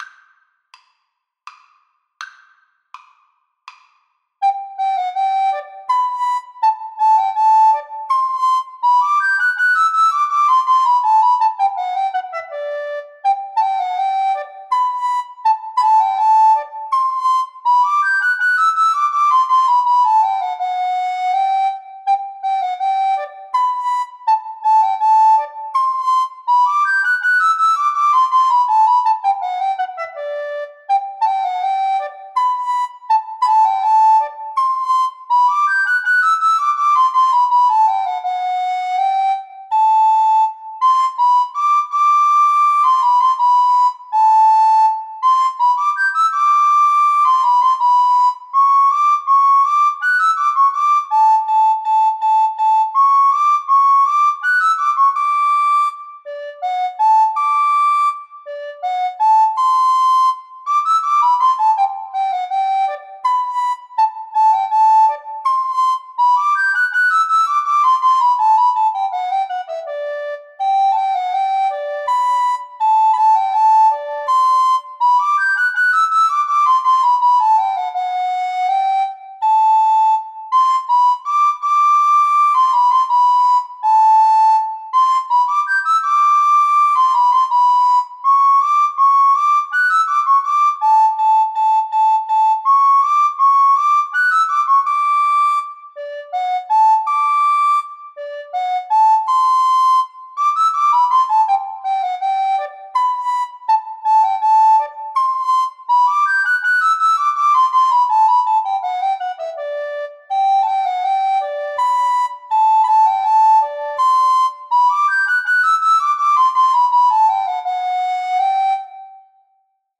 Descant Recorder 1Descant Recorder 2
3/4 (View more 3/4 Music)
Arrangement for Recorder Duet
G major (Sounding Pitch) (View more G major Music for Recorder Duet )
Classical (View more Classical Recorder Duet Music)